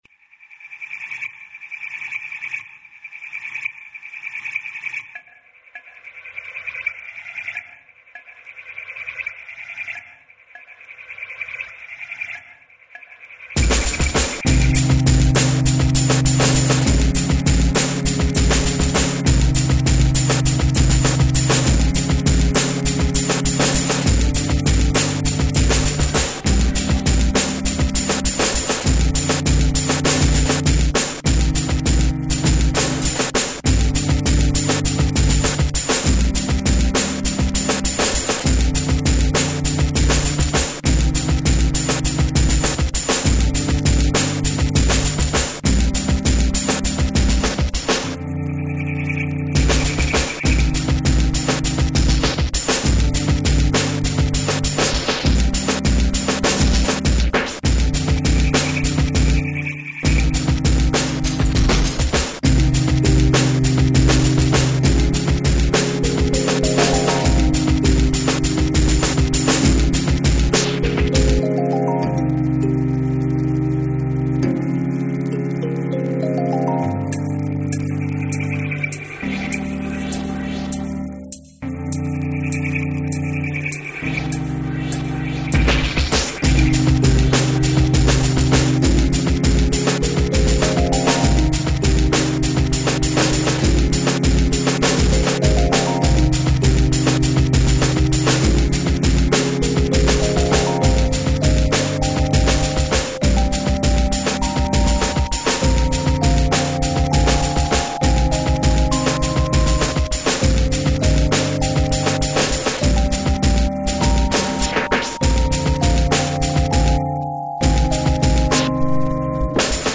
(2,50 Mb, 96Kbps, электроника, 2003)